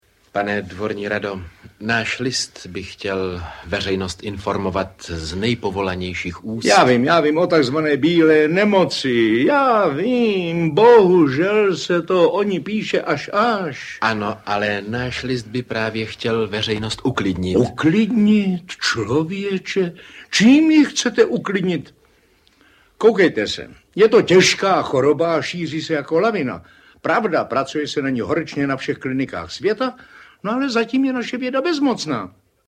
Ze Zlatého fondu Českého rozhlasu. Vynikající rozhlasová nahrávka z roku 1958, která převzala obsazení ze soudobého nastudování v Národním divadle v Praze.
bila-nemoc-audiokniha